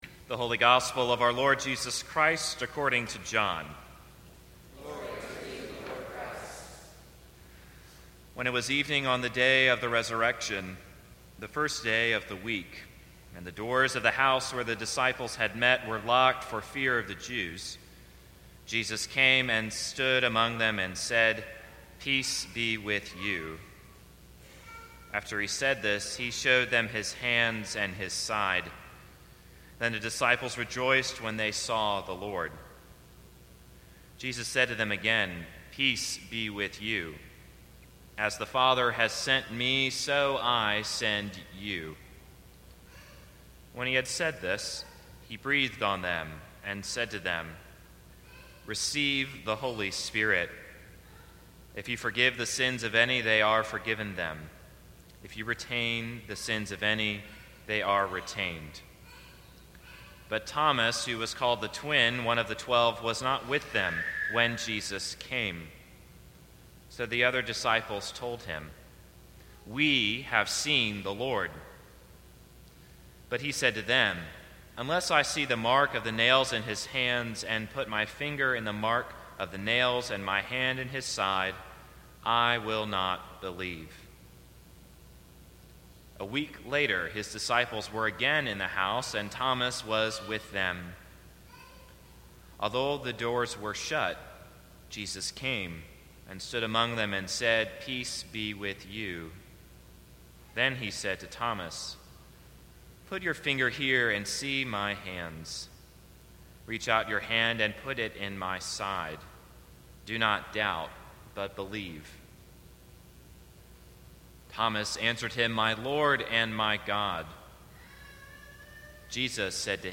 Sermons from St. Cross Episcopal Church What Would Jesus Say?